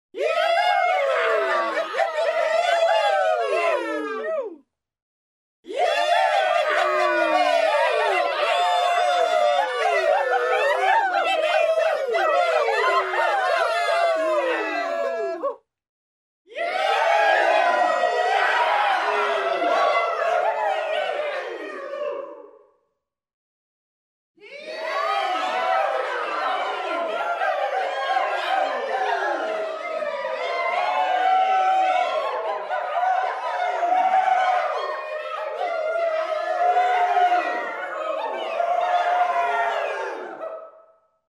Crowd of people
The sounds and noise of crowds
• Category: Crowd of people